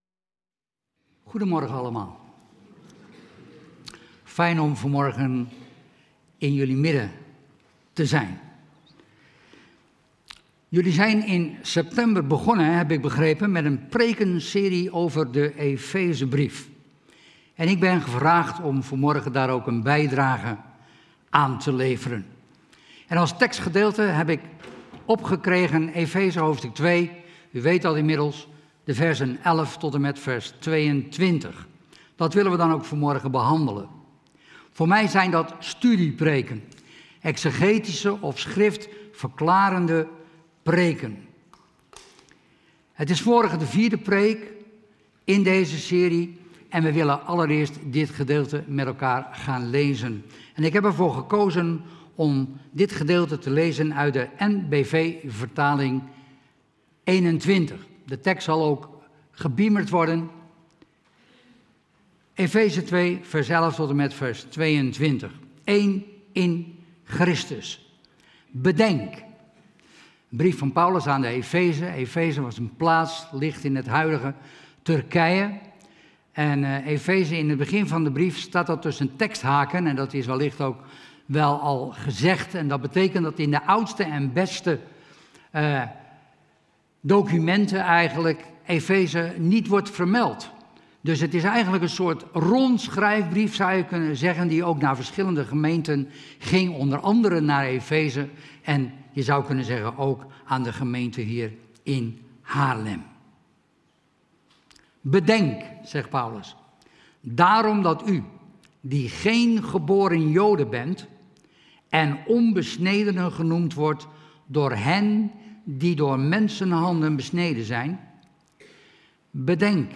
Het thema is: "1 + 1 = één". Ben jij benieuwd? luister dan snel deze aflevering of kijk de preek terug op ons YouTube kanaal.